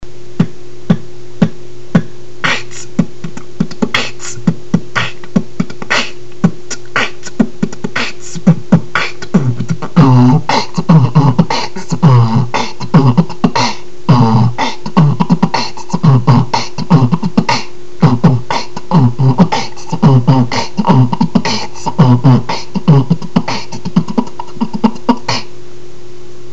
вот ещё на досуге битовал